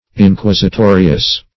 Search Result for " inquisitorious" : The Collaborative International Dictionary of English v.0.48: Inquisitorious \In*quis`i*to"ri*ous\, a. Making strict inquiry; inquisitorial.
inquisitorious.mp3